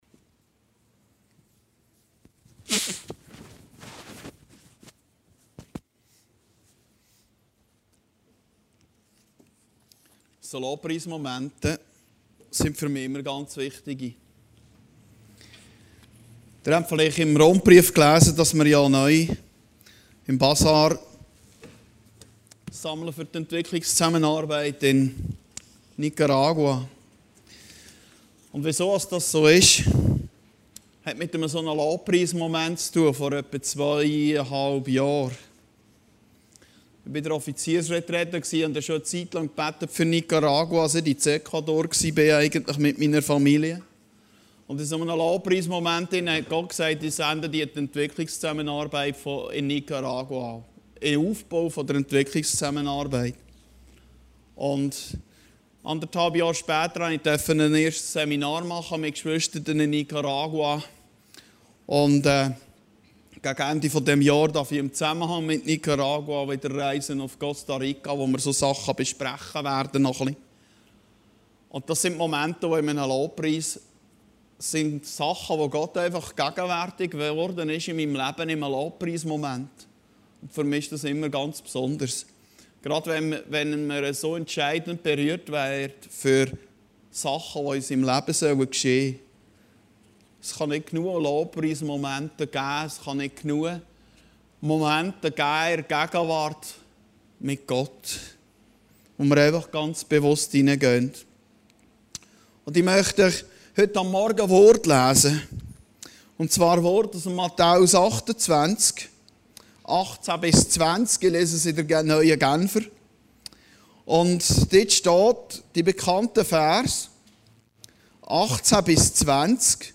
Predigten Heilsarmee Aargau Süd – In der Gegenwart Gottes leben